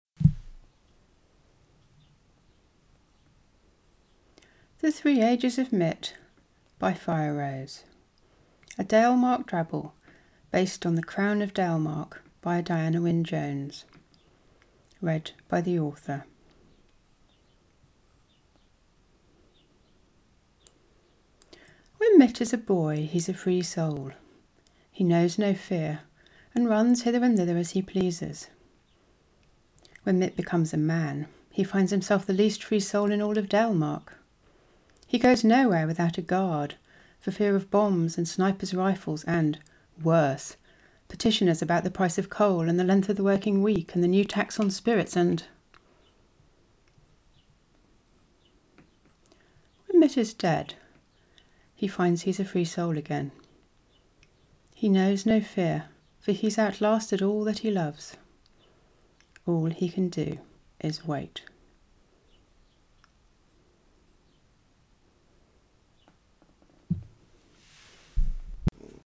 Bingo Square(s): No Editing, <10 Minutes Long
It really isn't edited, so listeners on headphones need to watch out for the spikes at start and end (0.25s & 1 min 08s) where I de-mute the mic. A bit breathy, lots of birdsong in the background.